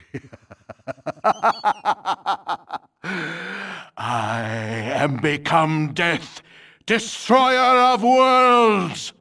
vs_fXzarxxx_haha.wav